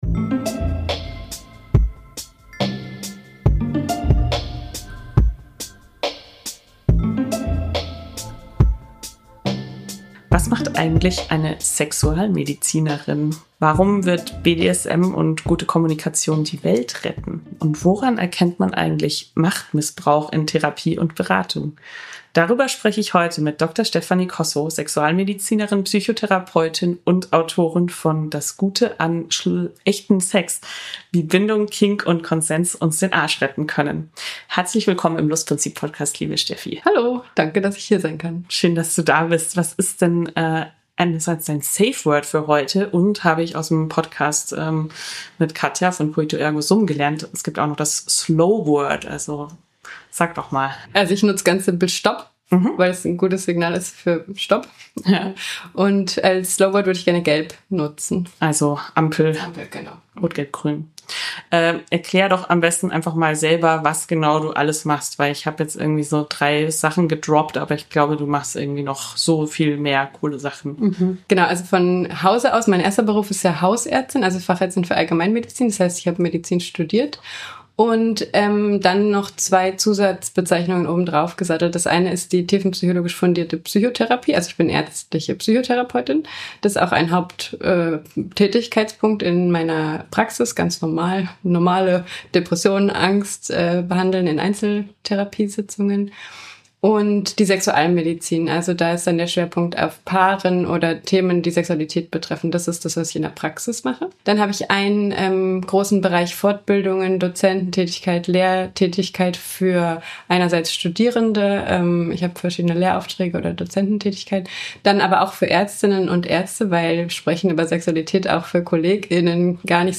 Wir reden auch über das dunkle Kapitel Machtmissbrauch in Therapie & Medizin – und darüber, was Betroffene tun können. Ein Gespräch über Lust, Grenzverletzungen und die Verantwortung von Profis.